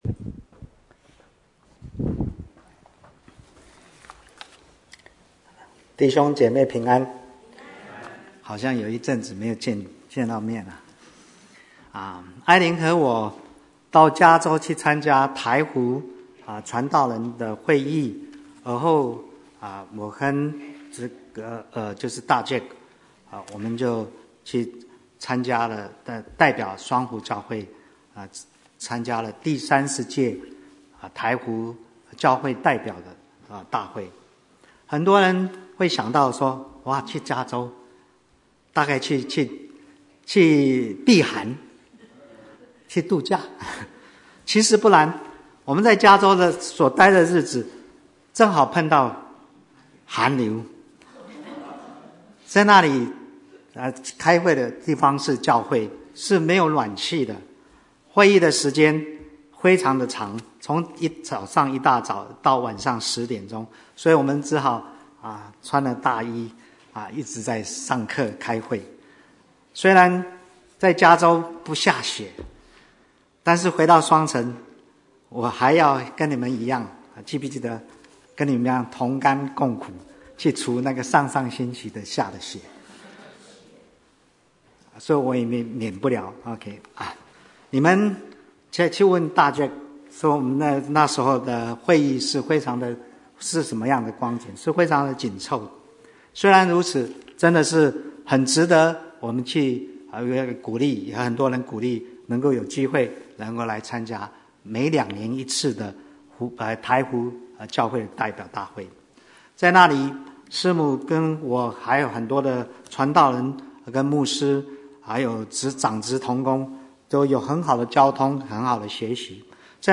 Bible Text: 羅馬書6:1-11 | Preacher: